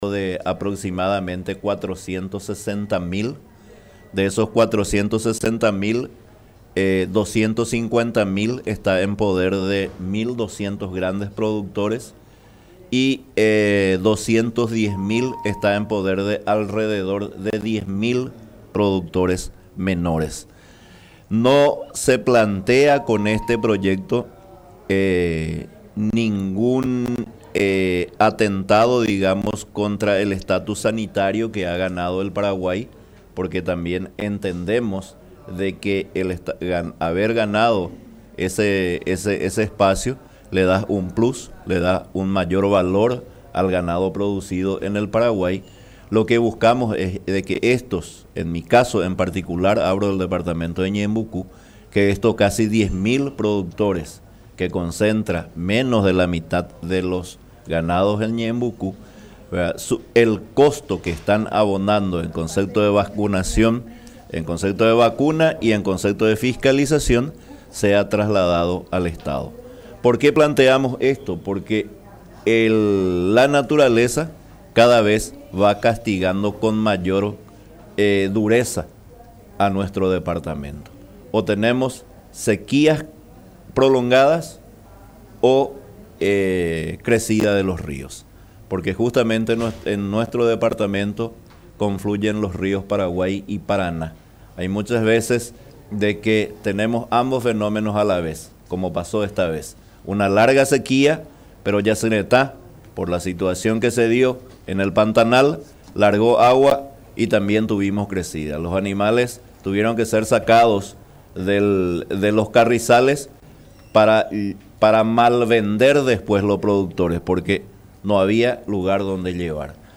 Diosnel Aguilera, diputado del departamento de Ñeembucú, hablo sobre el proyecto de ley “Sanitación Animal para pequeños ganaderos” presentado hoy con la intención de mejorar la sanidad ganadera y animal de la zona.